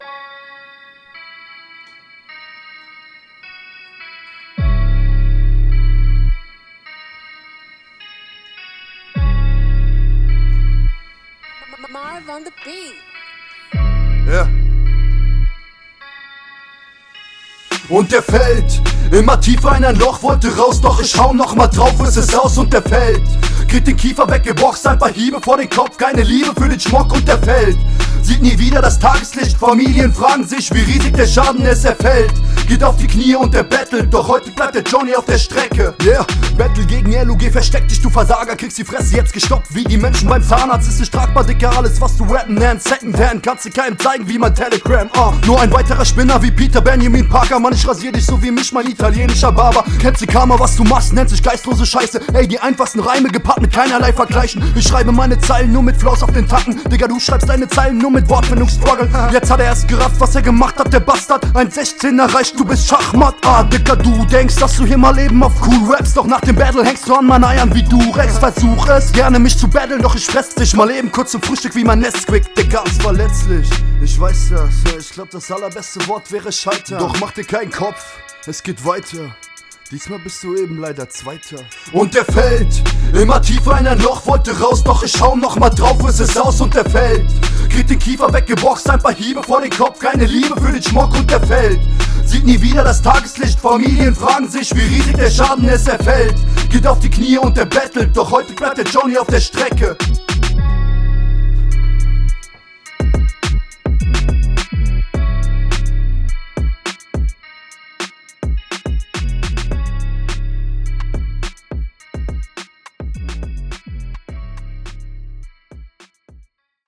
808 Type Dark Beat - cool Flow: Jo stabil Text: Zahnarzt Vergleicht kennt man schon …